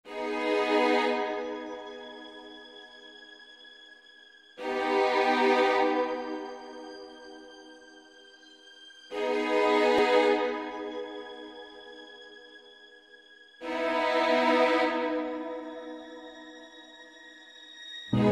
Download Suspense sound effects in mp3 format for free without login or sign-up and find similar sounds at Quick Sounds library.